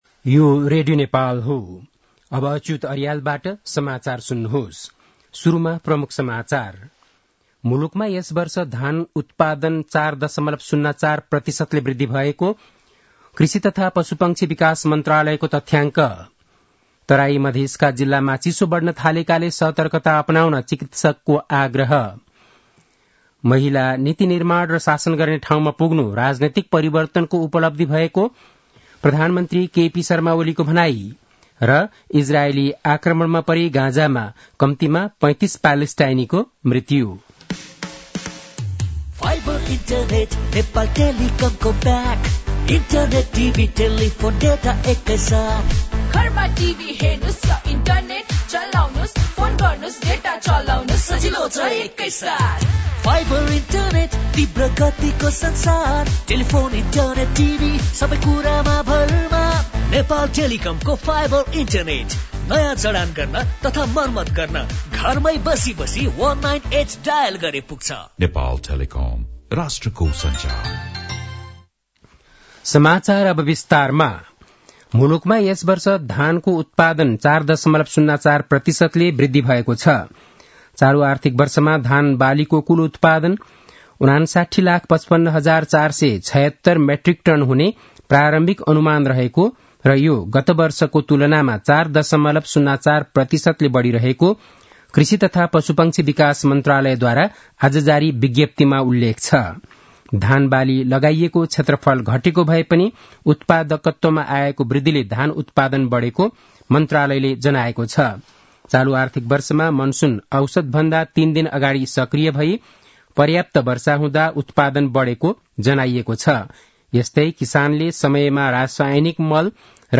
बेलुकी ७ बजेको नेपाली समाचार : २० पुष , २०८१
7-pm-nepali-news-9-19.mp3